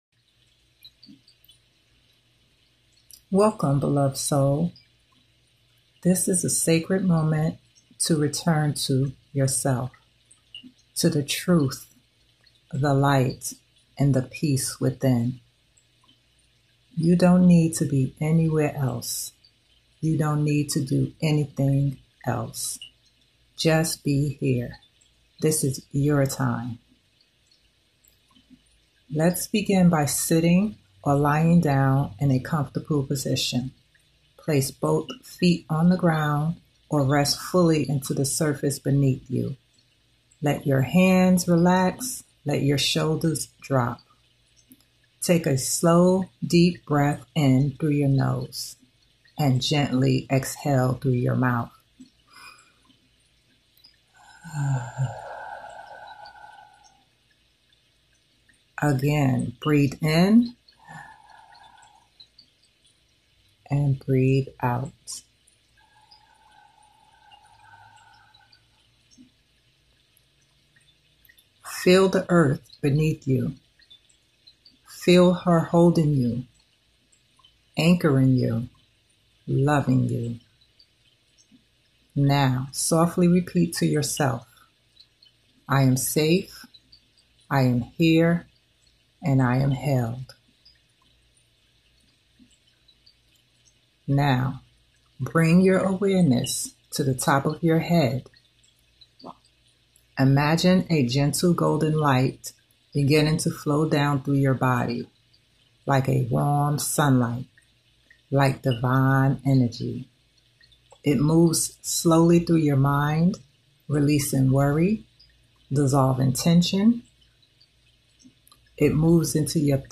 Audio Meditation